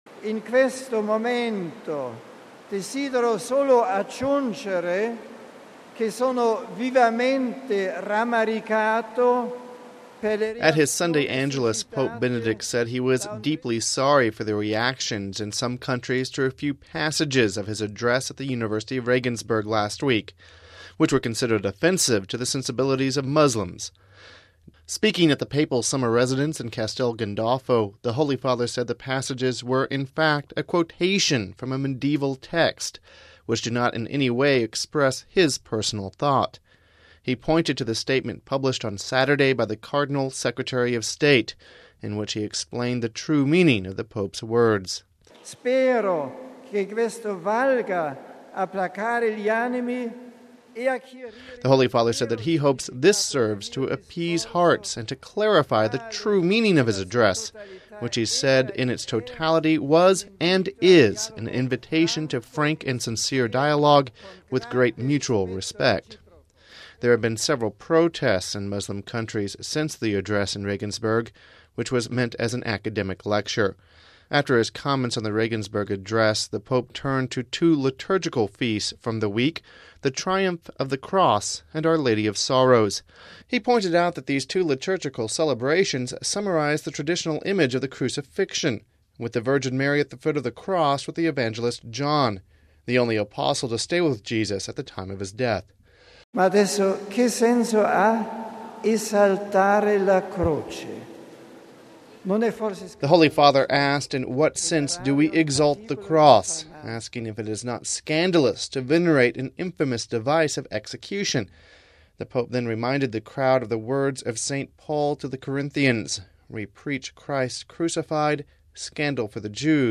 Home Archivio 2006-09-17 15:06:56 Sunday Angelus (17 Sept 06 - RV) Pope Benedict spoke about the reaction to his Regensburg lecture during his Sunday Angelus message.